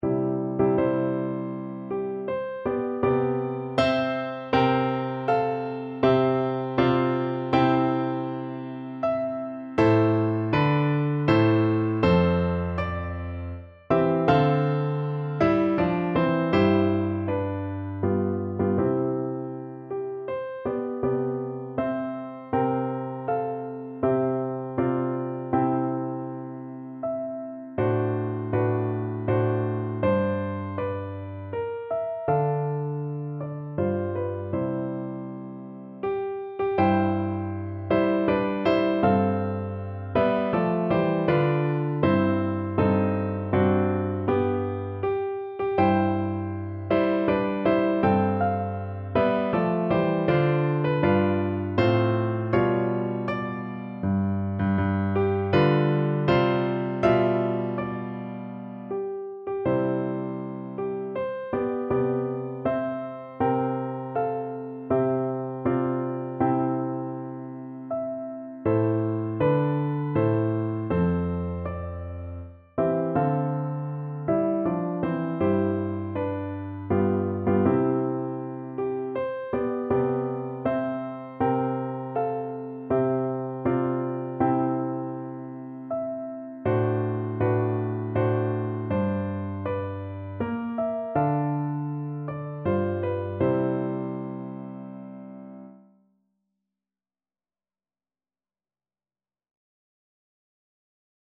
Free Sheet music for Piano Four Hands (Piano Duet)
Andante
3/4 (View more 3/4 Music)
Classical (View more Classical Piano Duet Music)